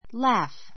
laugh 中 A1 lǽf ら ふ ｜ lɑ́ːf ら ー ふ （ ⦣ gh は f ふ と発音する） 動詞 （声を出して） 笑う 類似語 smile （ほほえむ）, giggle （くすくす笑う）, grin （にこっと笑う） laugh loudly laugh loudly 大声で笑う I laughed all through the funny movie.